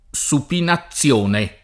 supinazione [ S upina ZZL1 ne ] s. f. (med.)